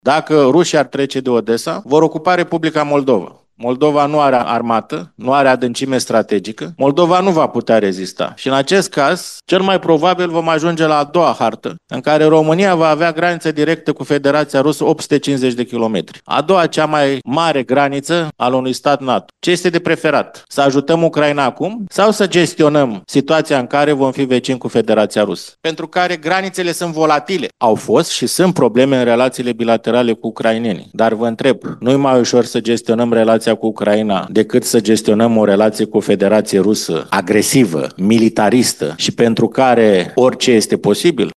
Nevoia ajutorului militar oferit Ucrainei de România a fost printre temele de discuție ale conferinței „Provocări de securitate în Balcani”, organizată la Timișoara.
În fața a zeci de specialiști în apărare și geopolitică, directorul general al New Strategy Center, George Scutaru, a spus că România este direct interesată ca Ucraina să reziste.